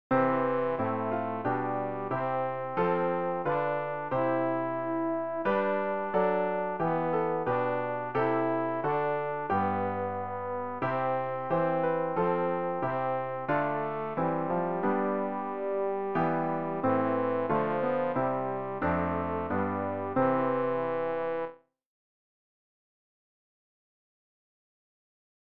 tenor-rg-369-gott-sei-dank-durch-alle-welt.mp3